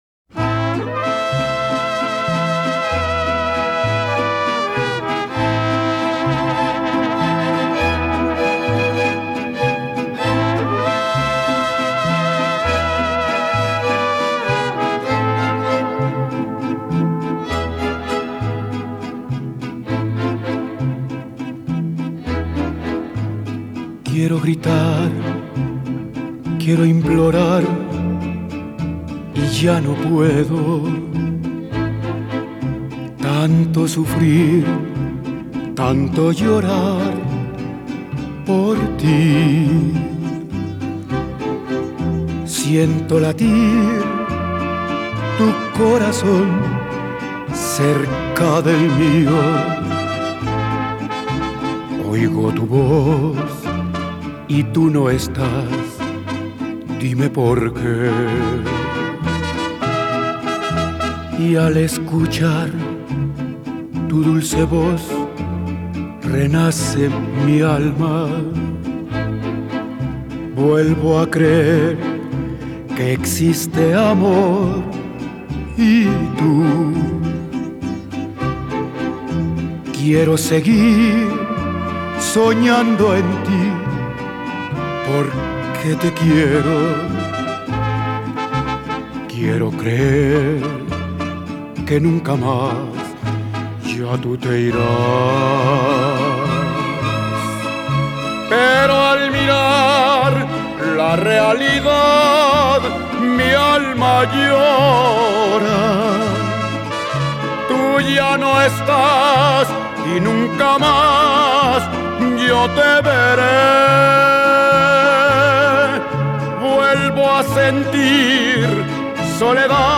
Esta vez la selección semanal fue un cover, ¡pero qué cover!